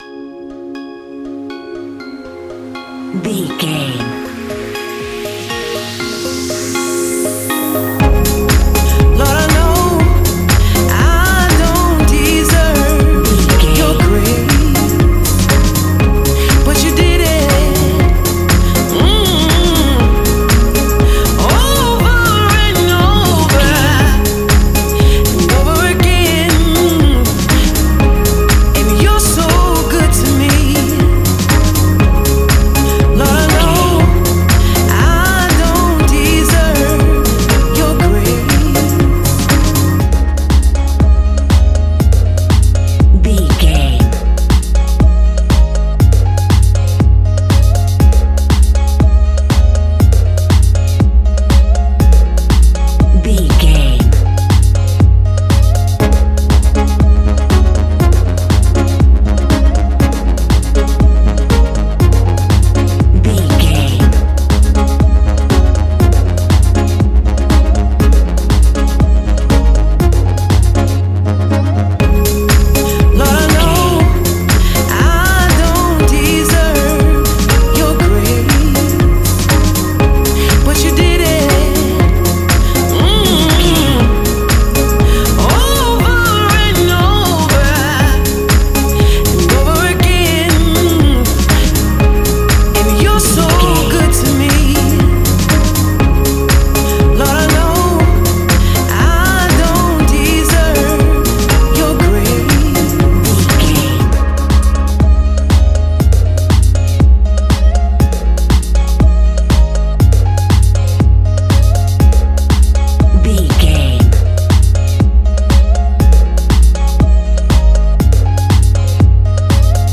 Ionian/Major
C♭
house
electro dance
synths
techno
trance